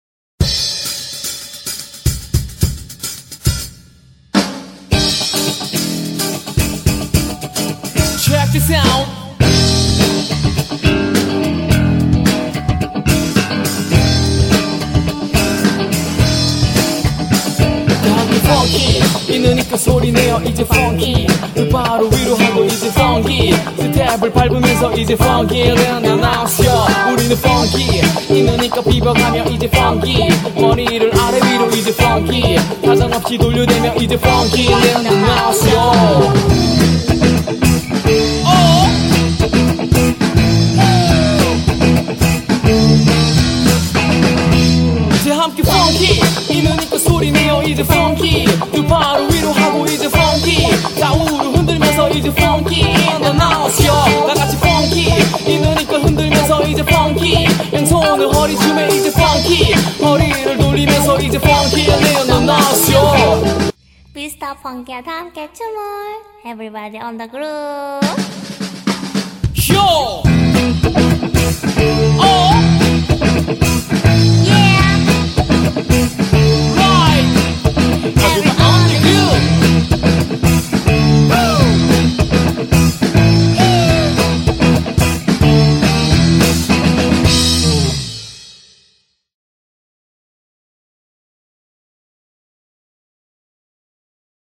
BPM106--1
Audio QualityPerfect (High Quality)